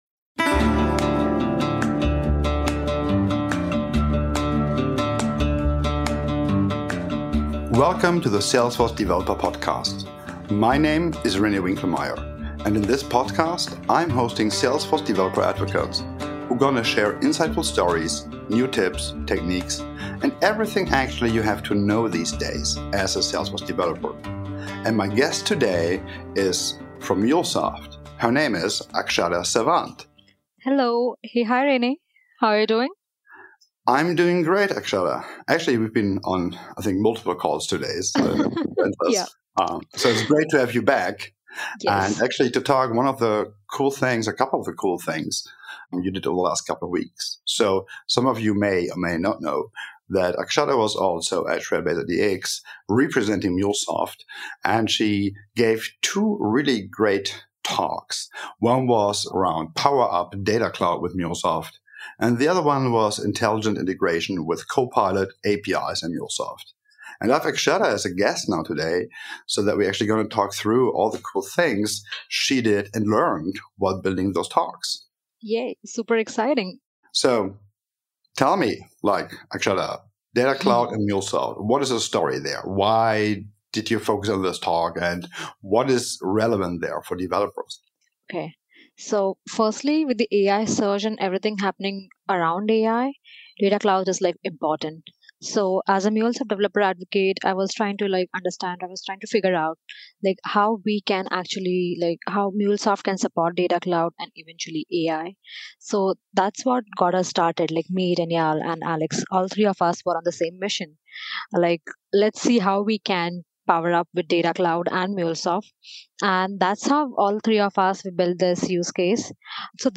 We then discuss MuleSoft APIs with Salesforce's Einstein Copilot. Don't miss out on this dialogue filled with the excitement of what's to come as we integrate Data Cloud, MuleSoft, and Einstein Copilot.